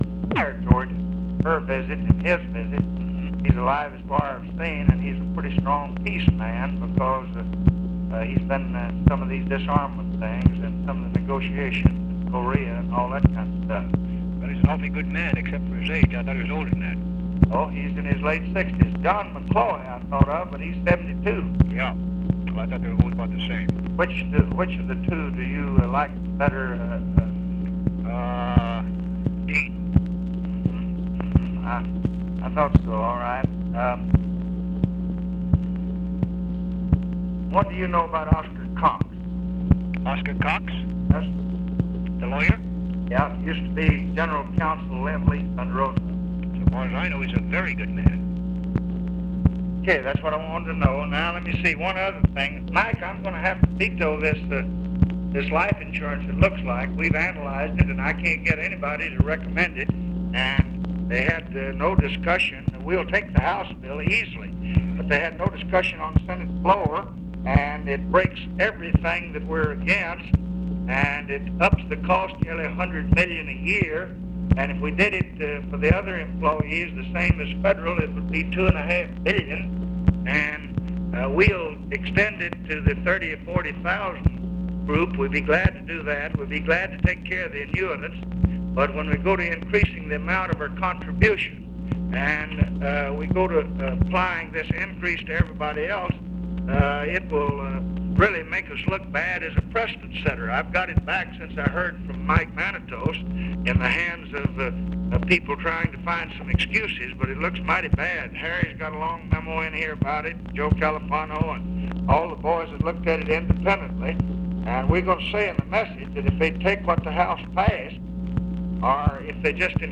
Conversation with MIKE MANSFIELD, September 12, 1966
Secret White House Tapes